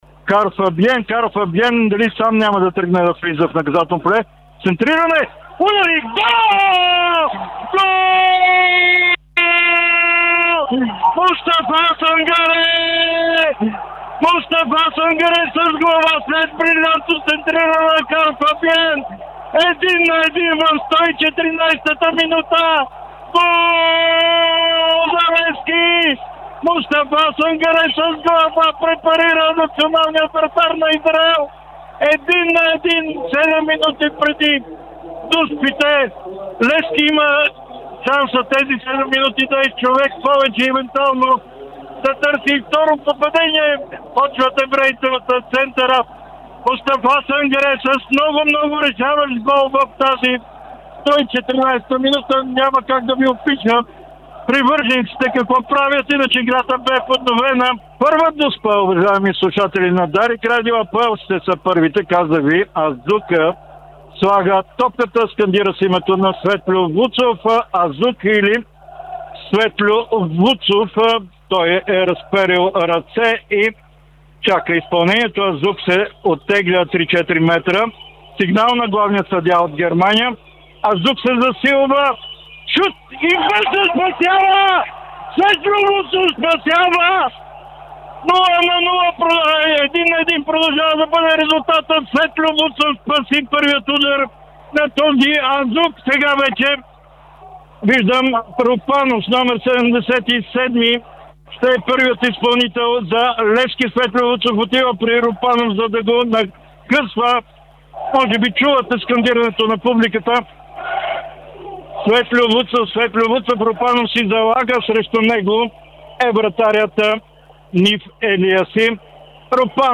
Еуфория в радиоефира: Левски елиминира Апоел Беер Шева след дузпи (АУДИО)